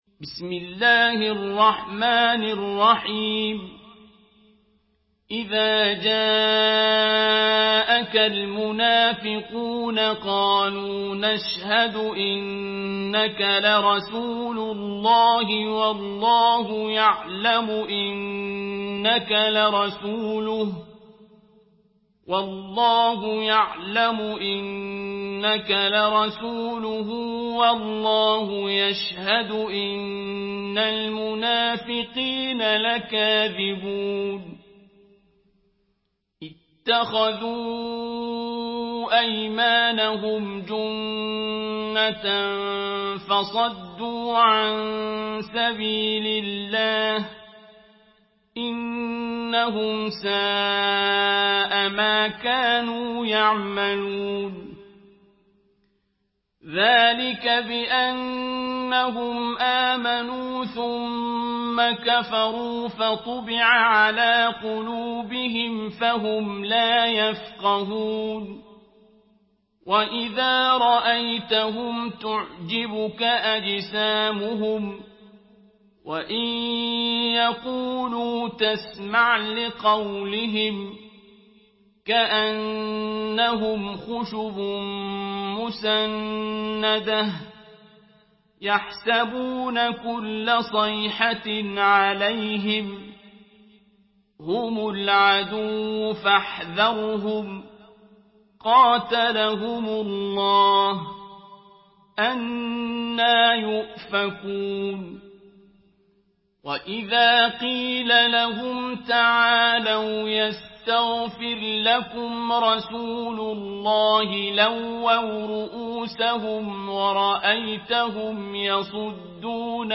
سورة المنافقون MP3 بصوت عبد الباسط عبد الصمد برواية حفص
مرتل